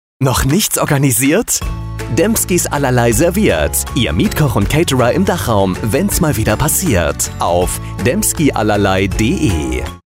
Funkspot_Demskis-Allerlei-10-Sek.mp3